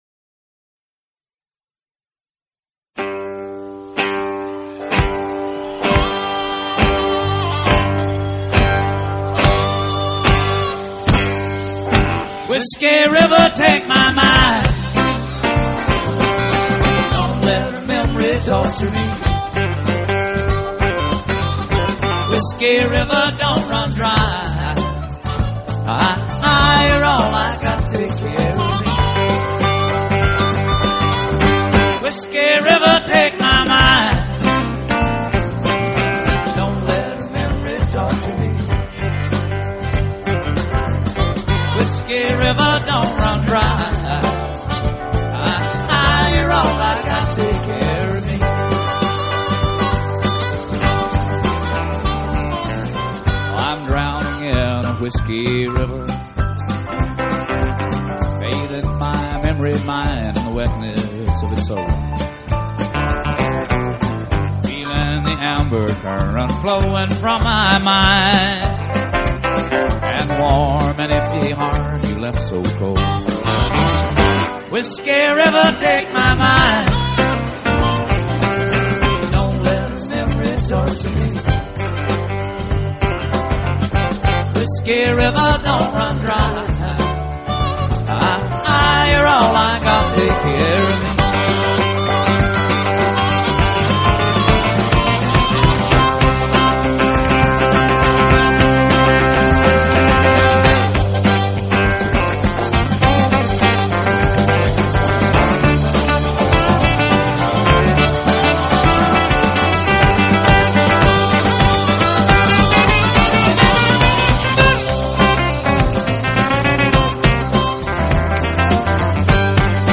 Country, Live